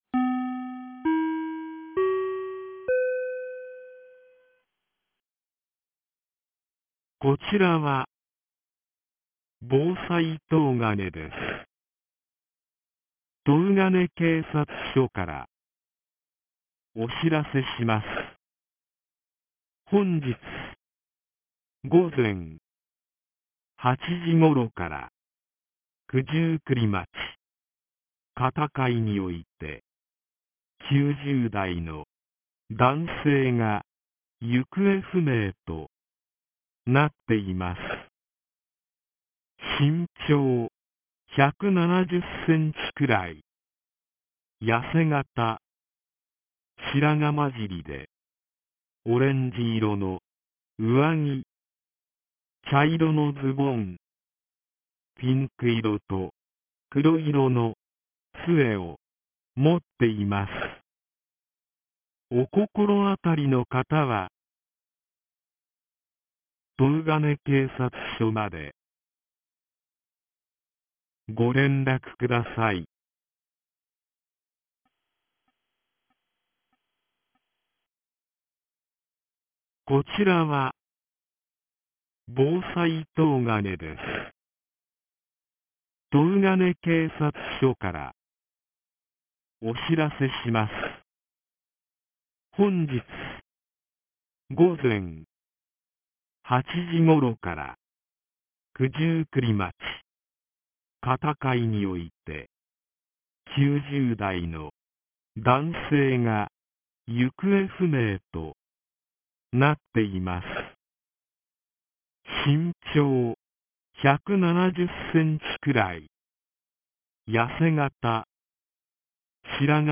2026年04月08日 17時03分に、東金市より防災行政無線の放送を行いました。